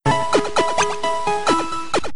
pokemon_heal.wav